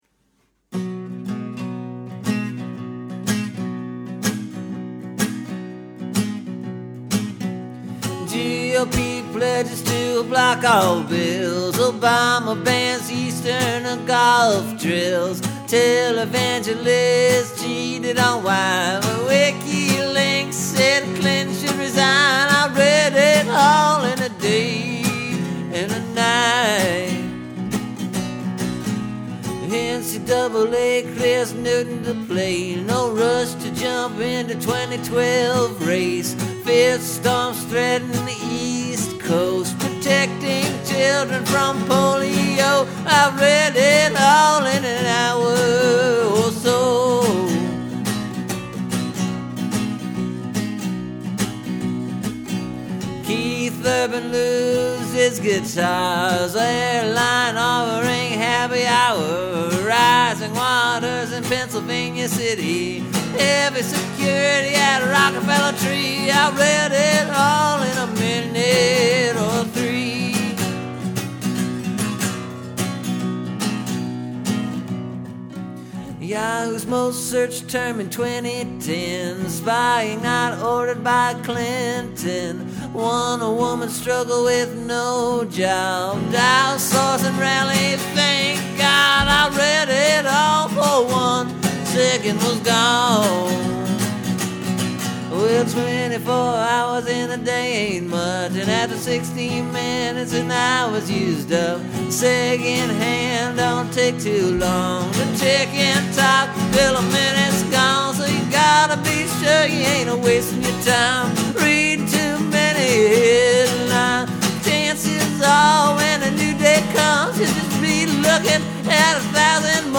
Anyway, I did this new version as a straight up blues tune. No talkin’ at all.